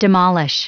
Prononciation du mot demolish en anglais (fichier audio)
Prononciation du mot : demolish